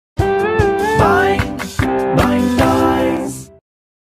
Boing Toys Sound Button - Free Download & Play
Pranks Soundboard964 views